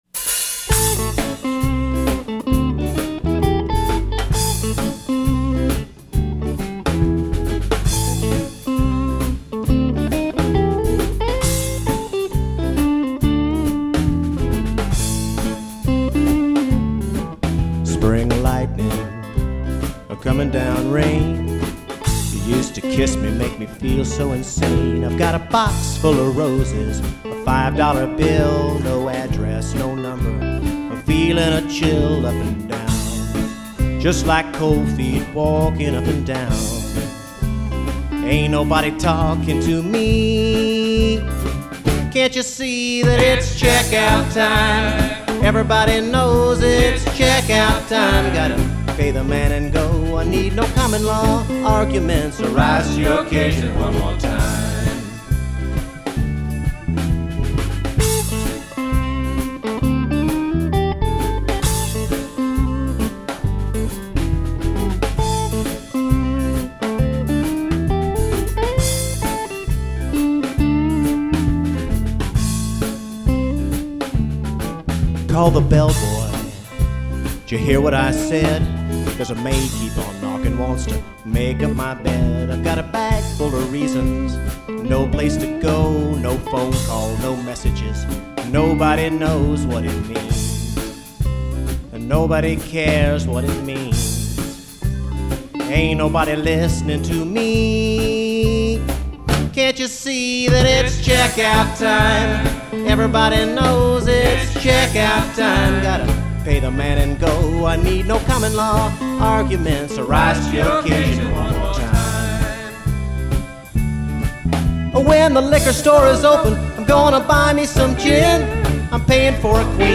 A very, very early one, a 13-bar blues.